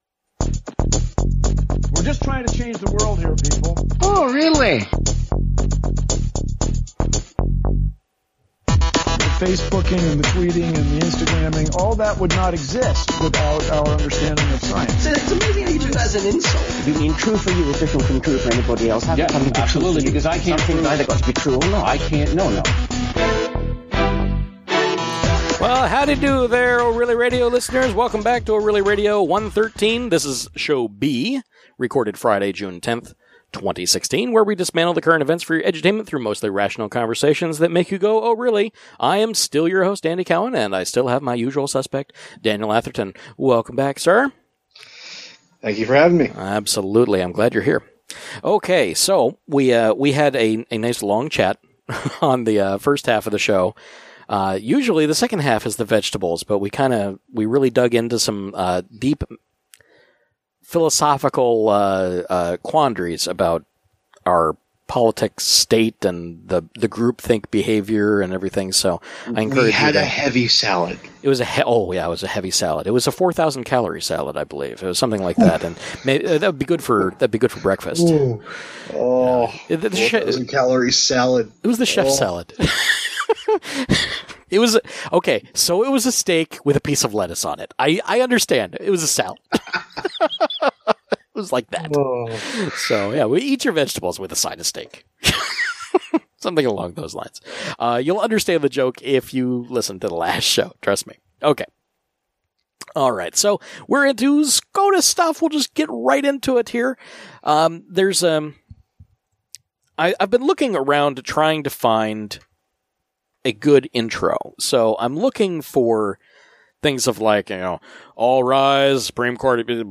Live every Friday night at about 9pm